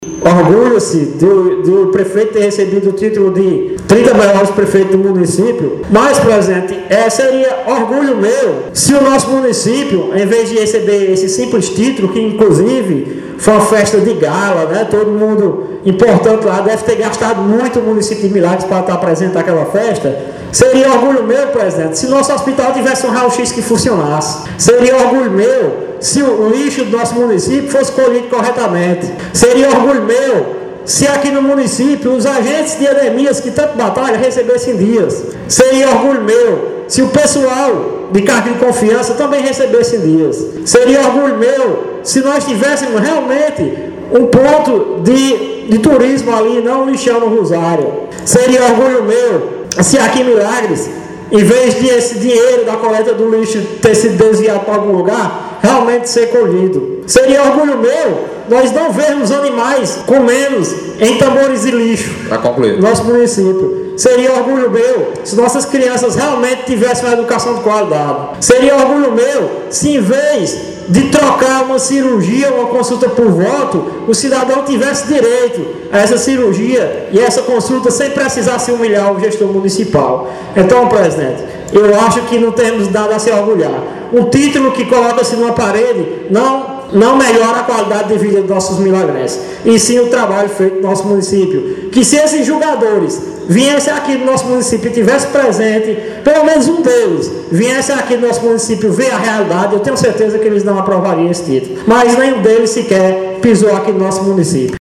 Já o vereador Giancles Filgueira (PTB) fez um discurso, intitulado de “Seria orgulho meu!”, para descrever que não concordava com a condecoração, em sua fala ele disse: